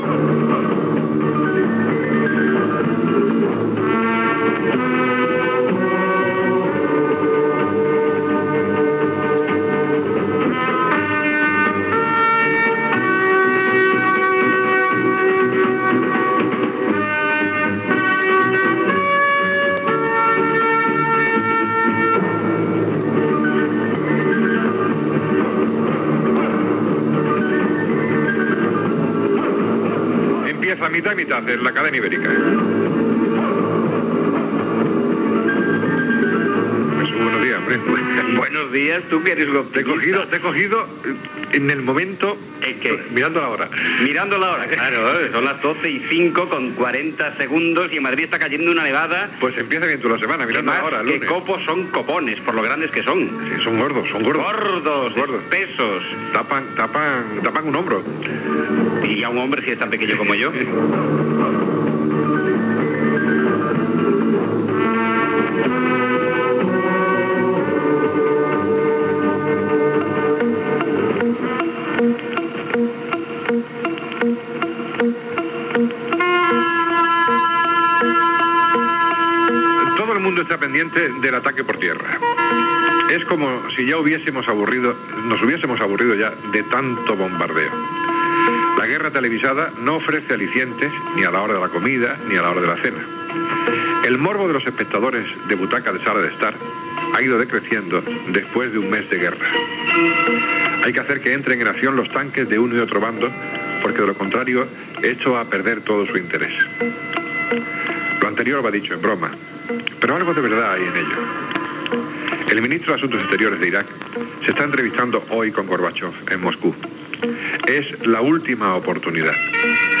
Sintonia, presentació (neva a Madrid), un mes de l'inici de la Guerra del Golf Pèrsic
Info-entreteniment